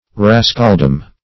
Search Result for " rascaldom" : The Collaborative International Dictionary of English v.0.48: Rascaldom \Ras"cal*dom\ (-d[u^]m), n. State of being a rascal; rascality; domain of rascals; rascals, collectively.
rascaldom.mp3